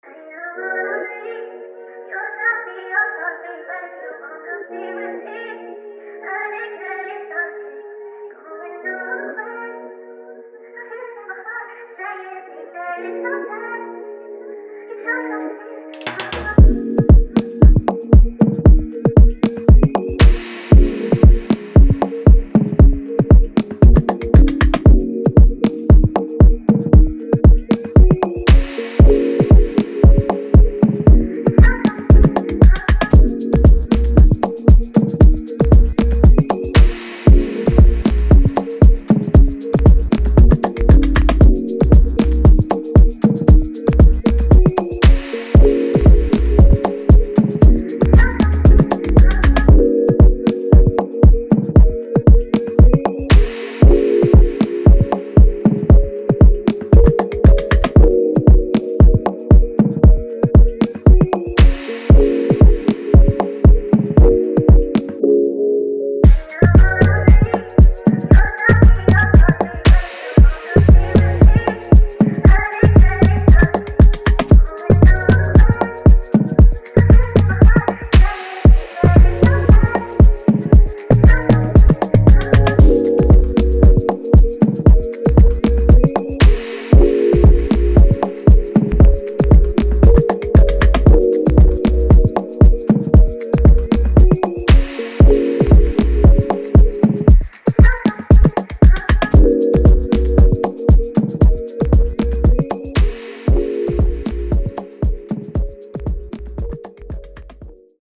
Afrobeat
D# Minor